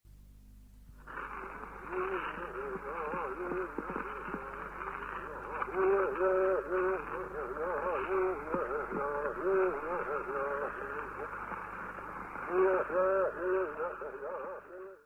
Canto de guerra y canto chamánico [canto 25-26]